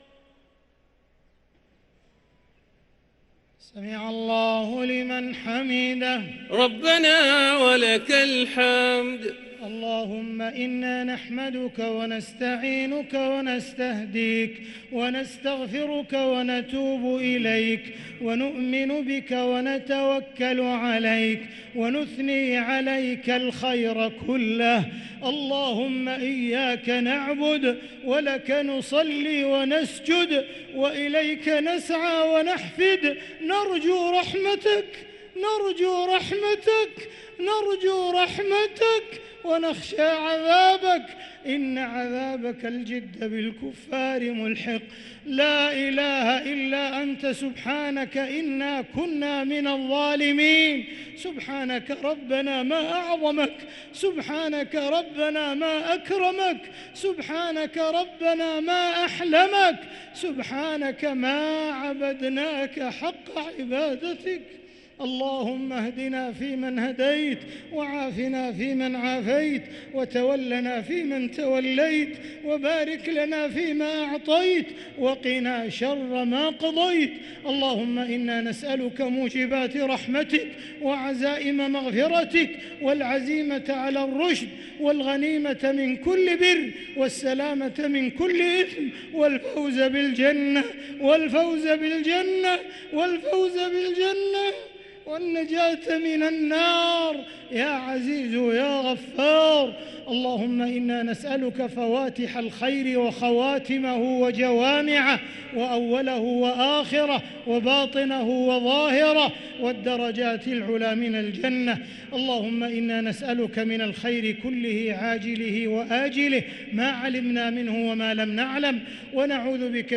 دعاء القنوت ليلة 10 رمضان 1444هـ | Dua 10 st night Ramadan 1444H > تراويح الحرم المكي عام 1444 🕋 > التراويح - تلاوات الحرمين